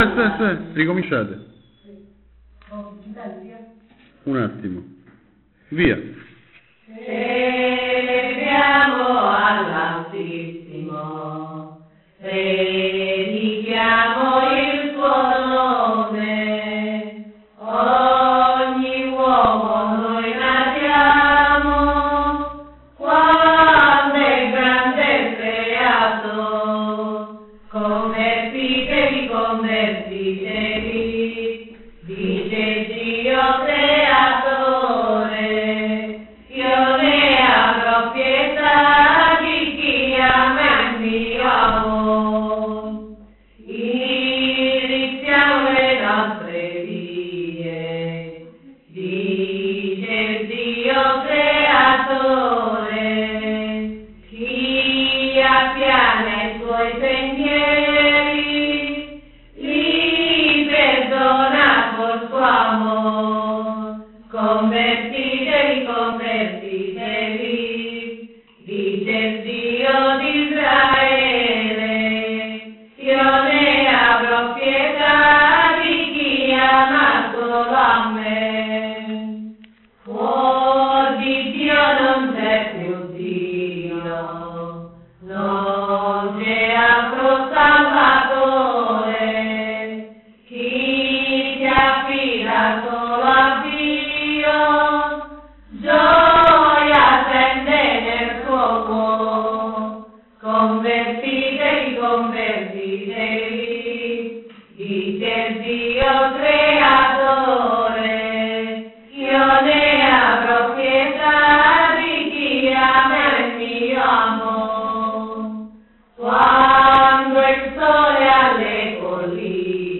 Apulian vernacular
Sannicandro Garganico
Female choir of the Jewish community in Sannicandro